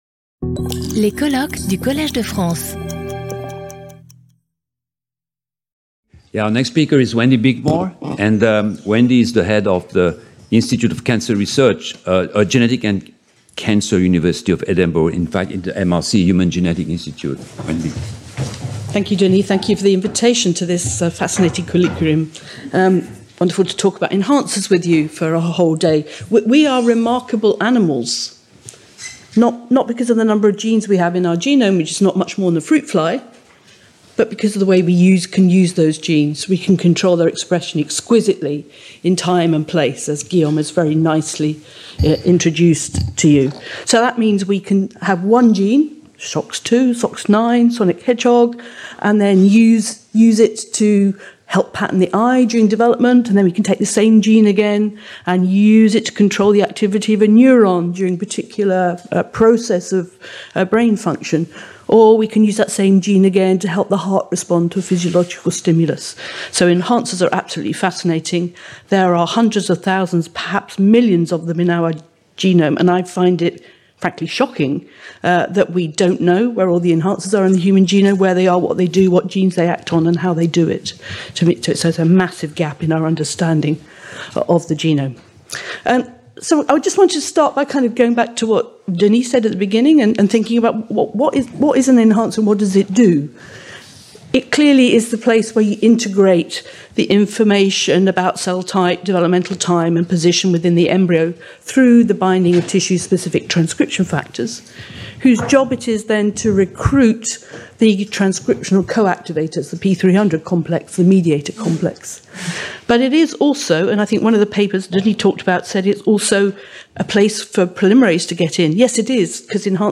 Speaker(s) Wendy Bickmore MRC Human Genetics Unit, Institute of Genetics and Cancer, University of Edinburgh, Scotland
Symposium